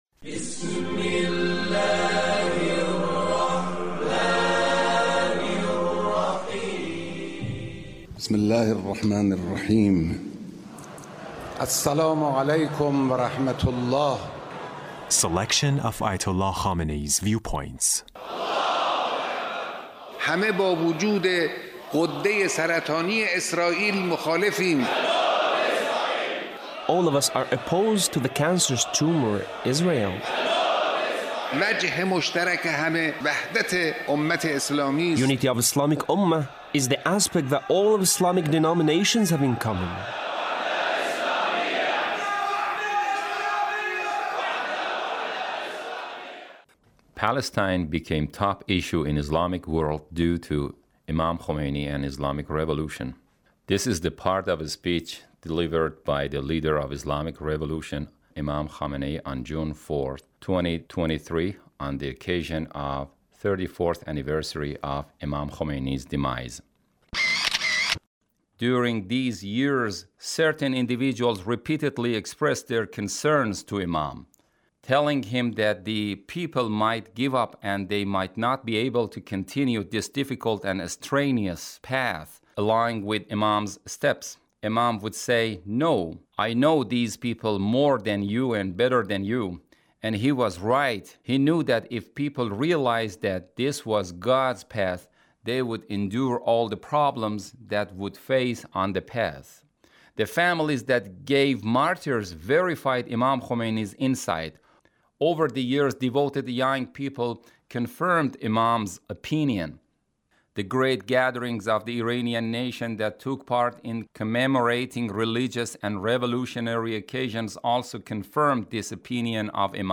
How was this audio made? Leader's Speech on the occasion of the 34th anniversary of Imam Khomeini’s demise. 2023